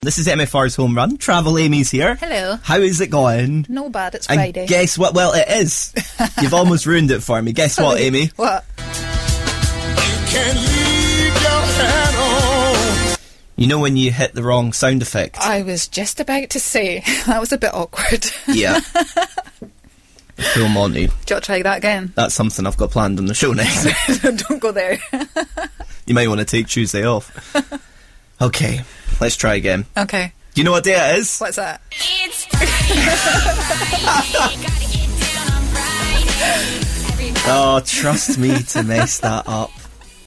WRONG SOUND EFFECT